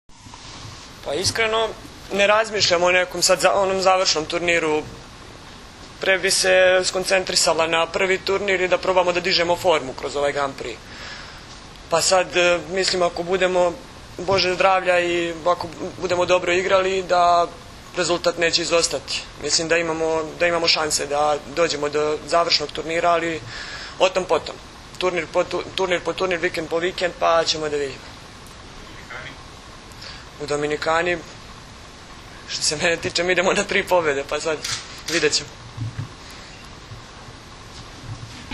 danas je u beogradskom hotelu “M” održana konferencija za novinare kojoj su prisustvovali Zoran Terzić
IZJAVA